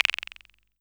Quijada.wav